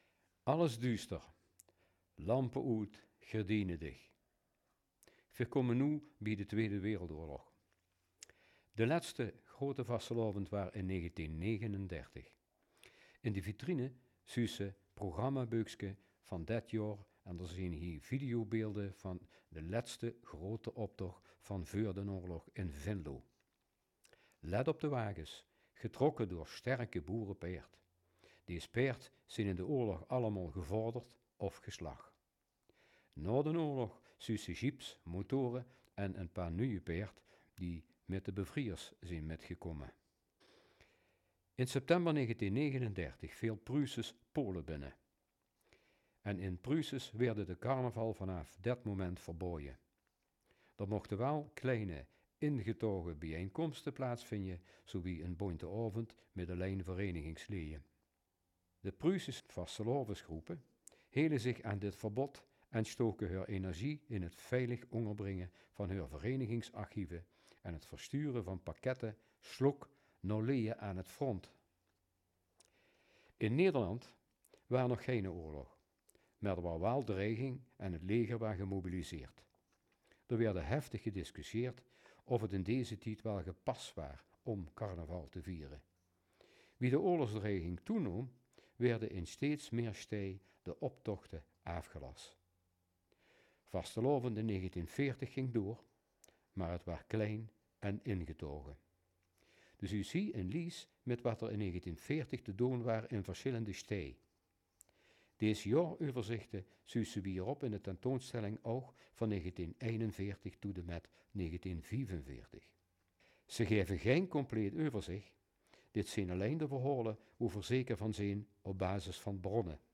Luister in het Roermonds:
roermond-audiotour-6.m4a